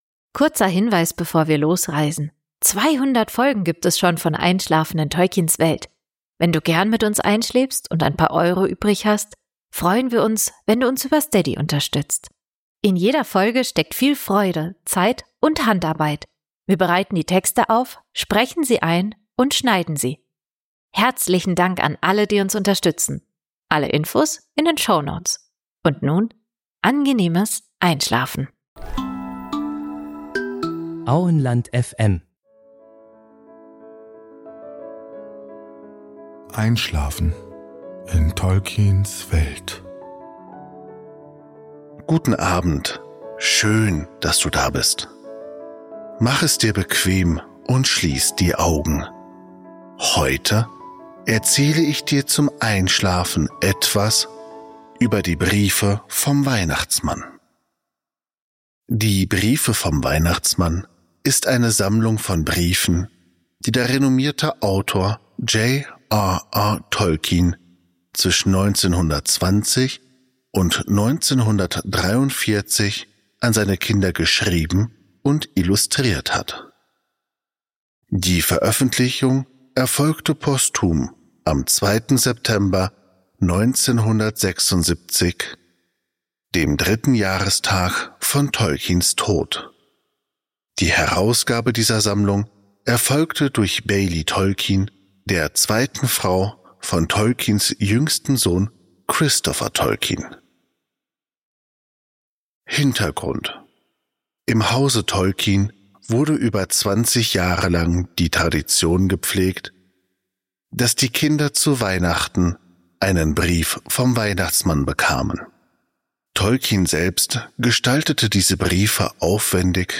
Gutenachtgeschichten